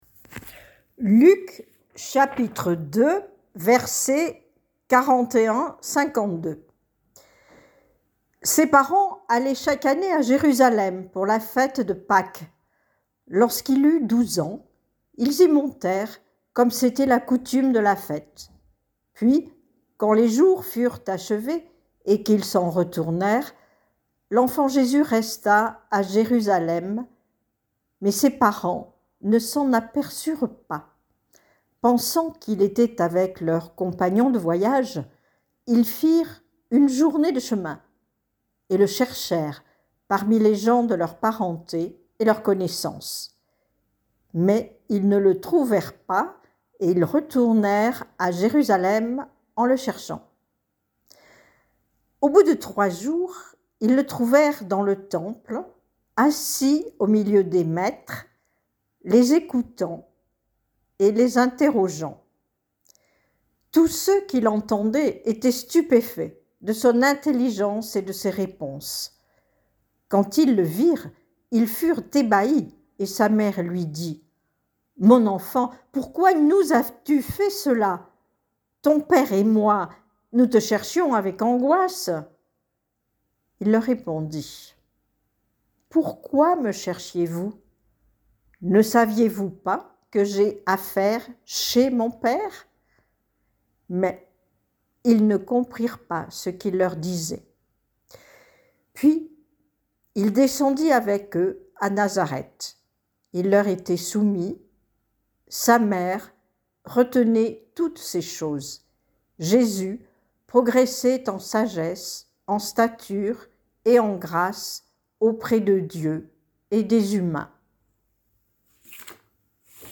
" Et si nous faisions signe? " Culte du 29 décembre 2024